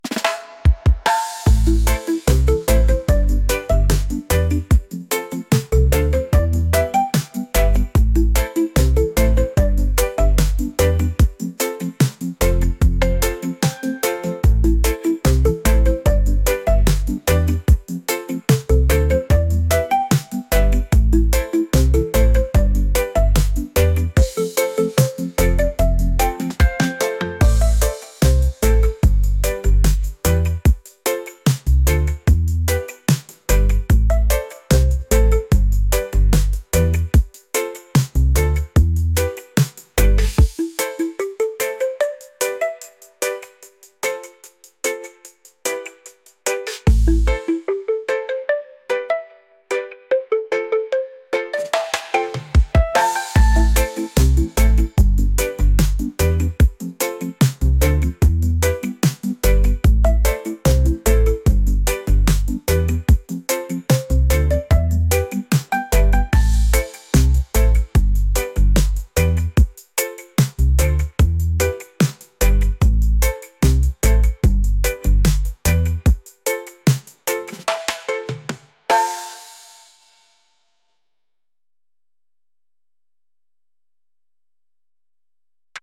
vibes | laid-back | reggae